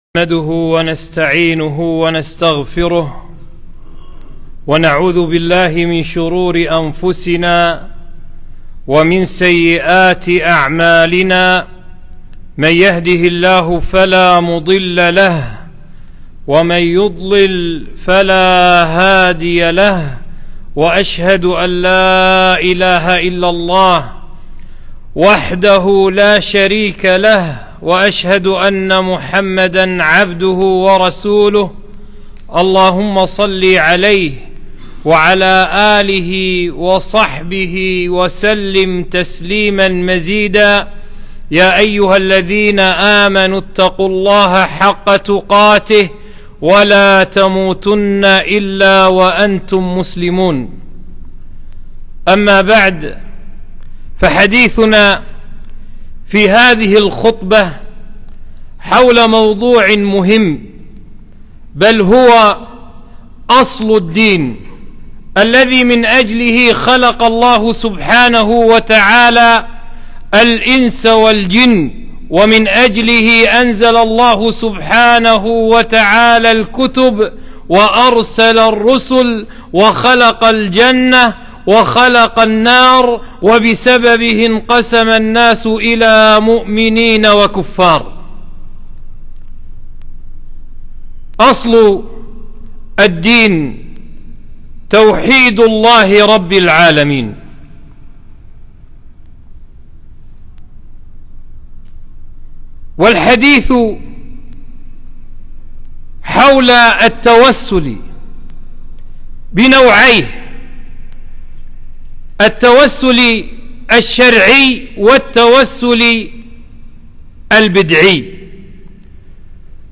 خطبة - التوسل، أقسامه وحكم كل قسم